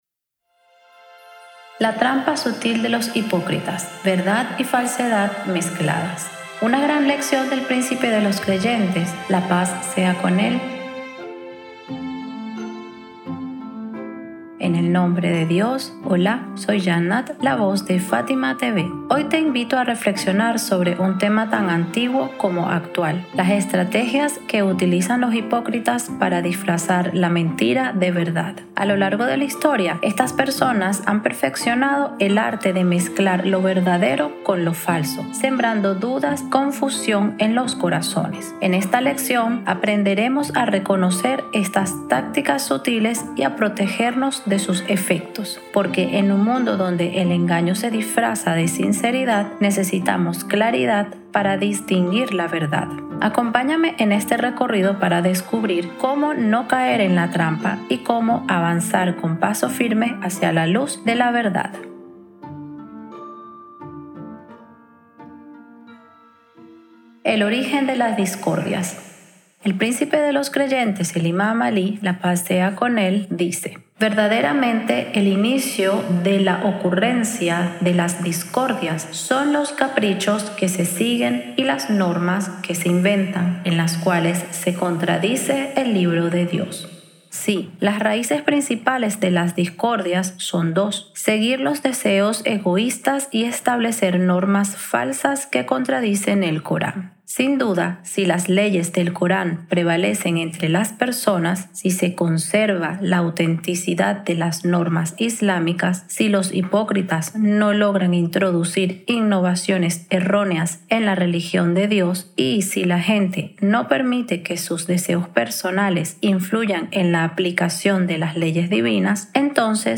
🎙 Locutora